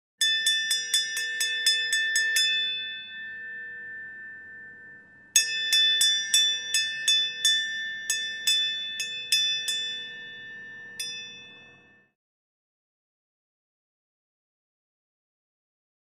Ship Bell Dings, Close Perspective.